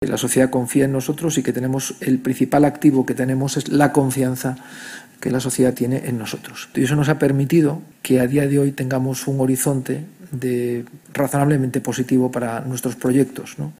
La sede madrileña de Fundación ONCE reunió a estas 25 empresas y entidades en el  acto ‘Lazos de futuro’, celebrado el 18 de noviembre de manera presencial y telemática, con el objetivo de expresar públicamente el agradecimiento de las fundaciones del Grupo Social ONCE a los particulares, empresas e instituciones que colaboran con ellas a través de programas, proyectos e iniciativas que ayudan a avanzar en la igualdad de los derechos de las personas con discapacidad y sus familias, y por su compromiso con la mejora de la calidad de vida de las personas con discapacidad.